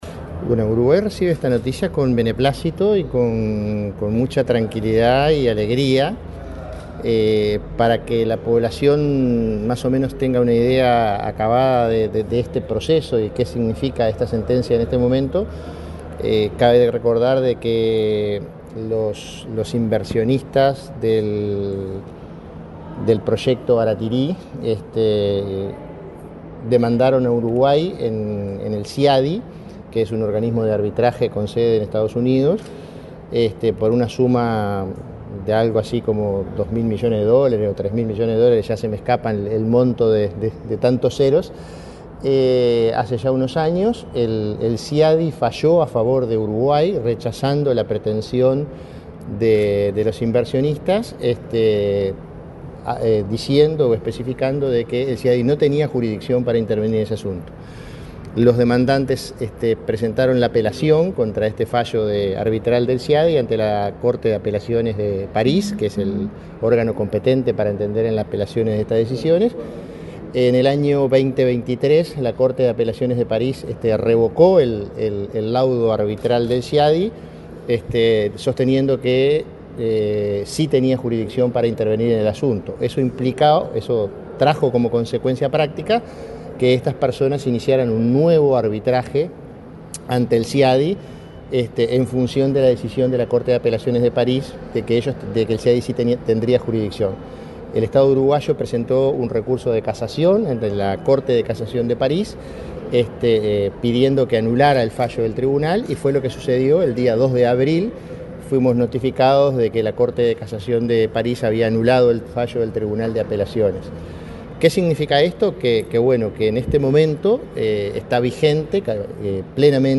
Declaraciones del prosecretario de la Presidencia, Jorge Díaz
El prosecretario de la Presidencia, Jorge Díaz, dialogó con la prensa en la Torre Ejecutiva, acerca del reciente fallo de un tribunal de casaciones en